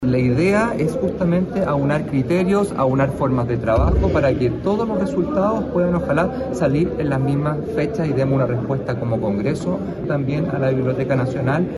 En una improvisada declaración a los medios en el cuarto piso de la Cámara, el presidente de la corporación, José Miguel Castro, explicó que el Congreso, con sus tres estatutos, debe buscar seguir con el mismo procedimiento.